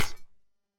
Splatter Impact
A wet splatter impact with liquid spray and dripping aftermath
splatter-impact.mp3